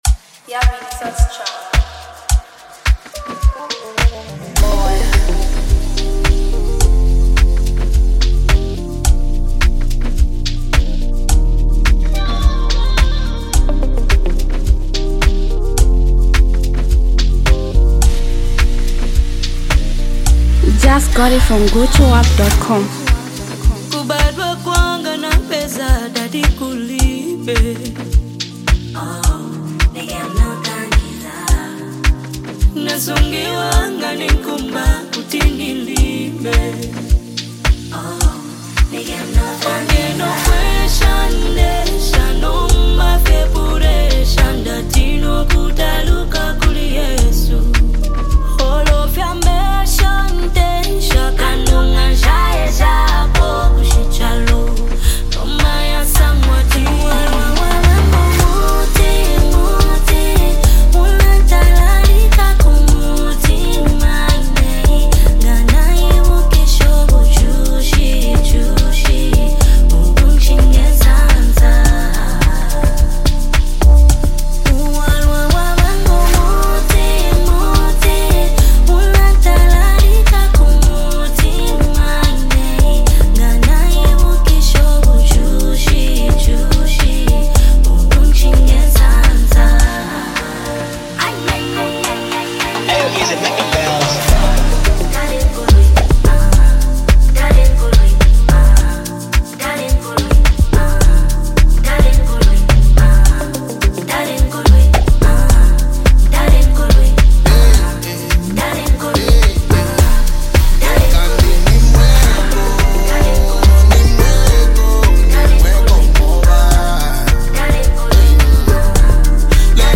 soulful song
soulful sounds of Zambian music